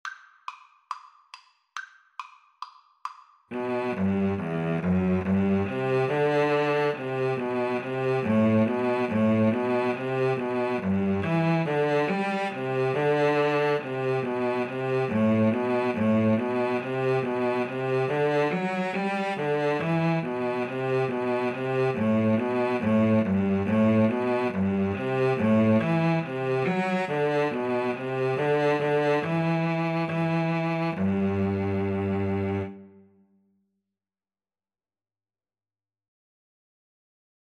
Play (or use space bar on your keyboard) Pause Music Playalong - Player 1 Accompaniment reset tempo print settings full screen
G major (Sounding Pitch) (View more G major Music for Cello Duet )
2/2 (View more 2/2 Music)
Cello Duet  (View more Easy Cello Duet Music)